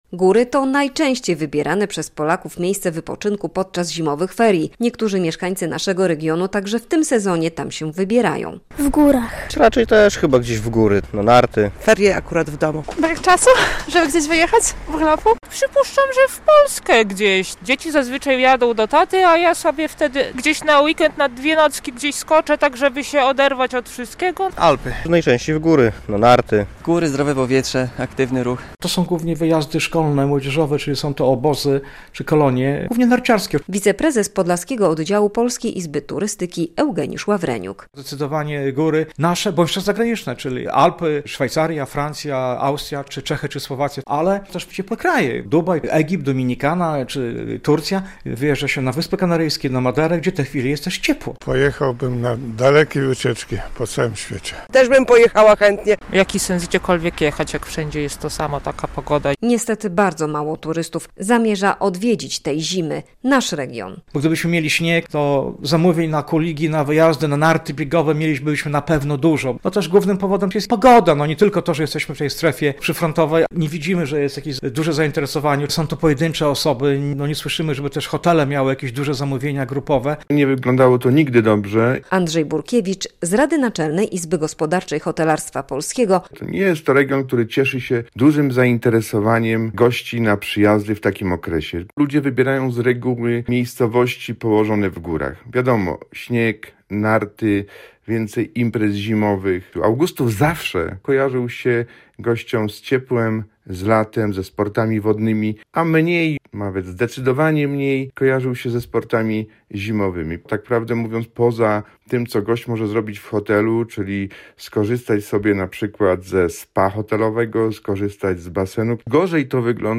Ferie 2025 w Podlaskiem - relacja